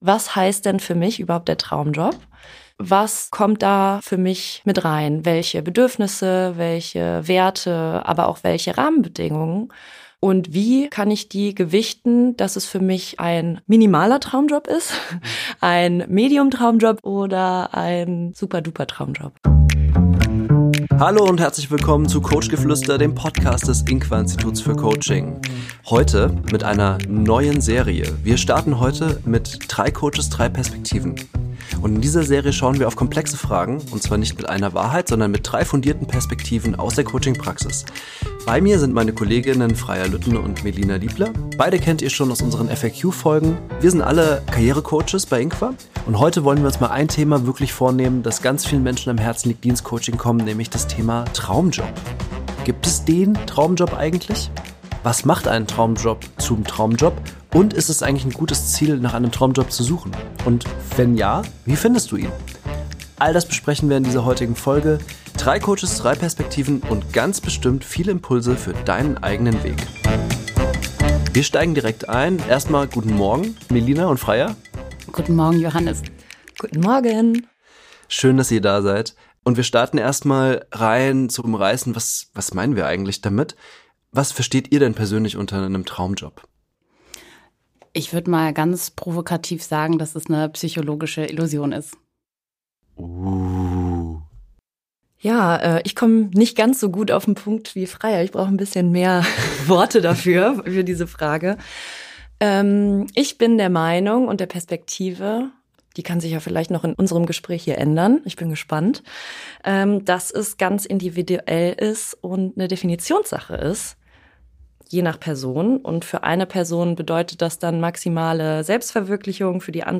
Genau über diese Entwicklungen sprechen die drei Coaches – offen, reflektiert und nah an der Praxis.